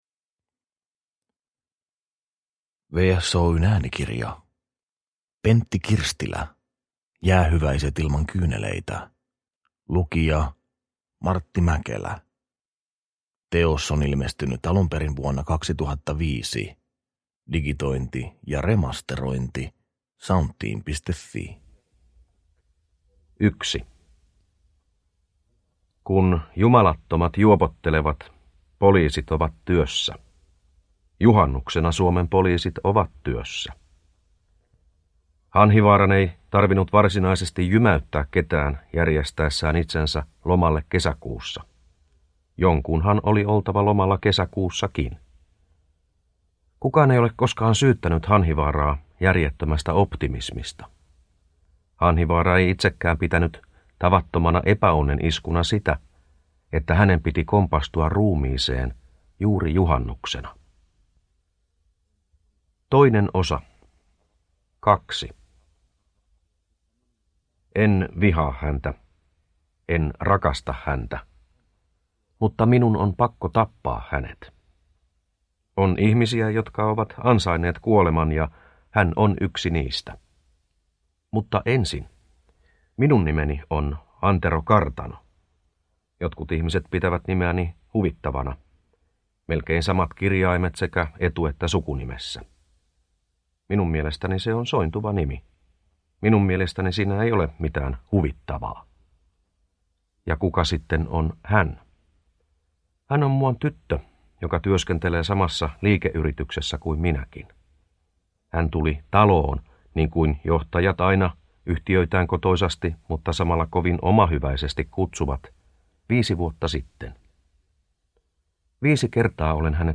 Jäähyväiset ilman kyyneleitä – Ljudbok – Laddas ner